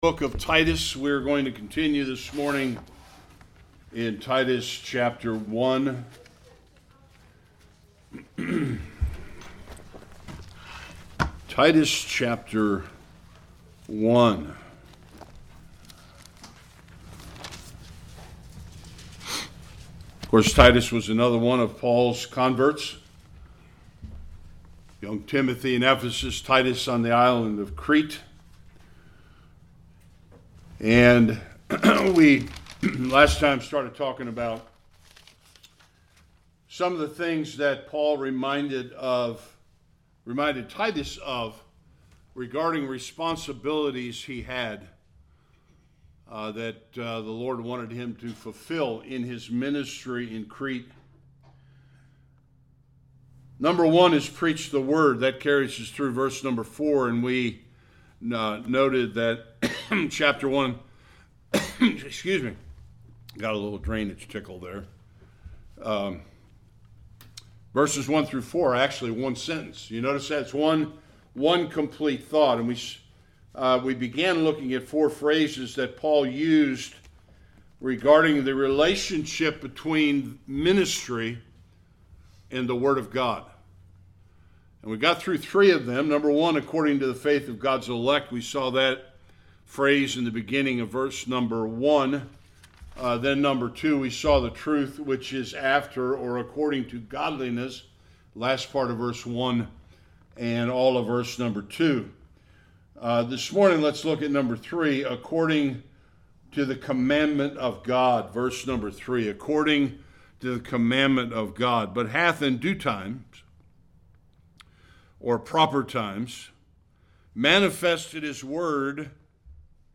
4-6a Service Type: Sunday Worship The Apostle Paul instructs Titus to appoint elders in Crete.